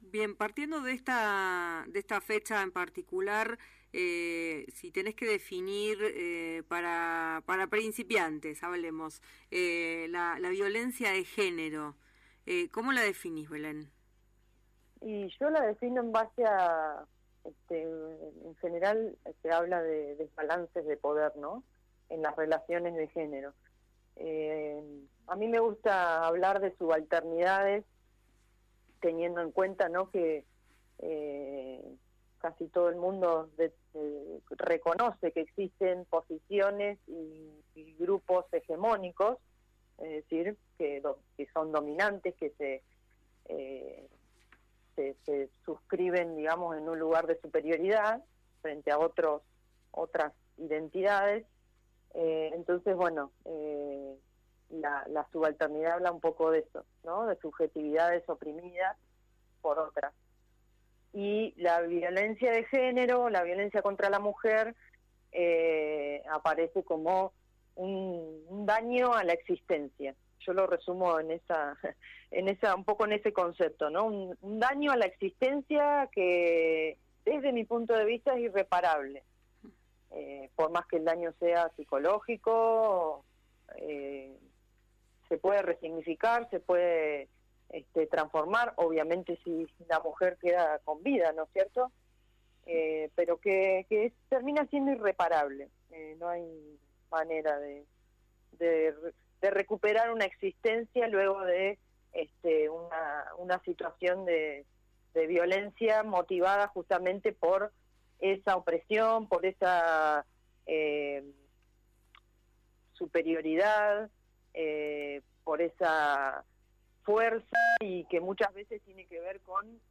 En diálogo con Aire de Pueblo, la politóloga y magíster en género,